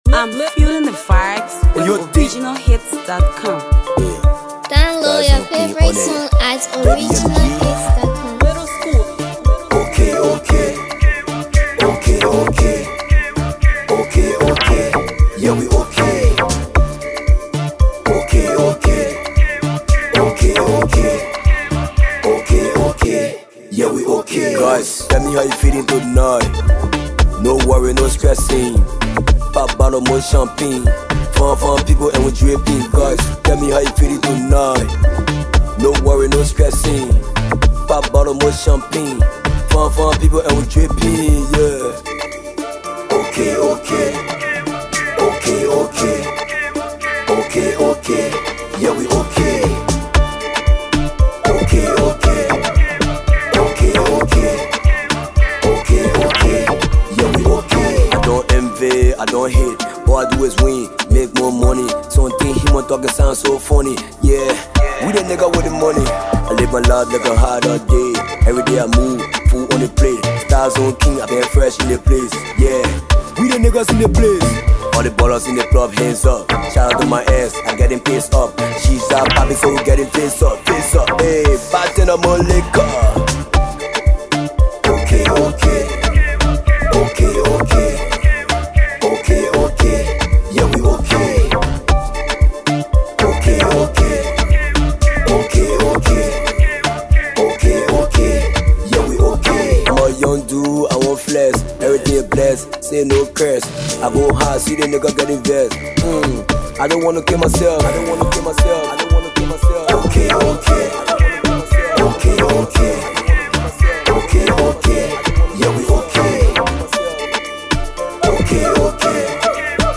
This vibrant track
With its catchy beats and engaging lyrics
Afro Pop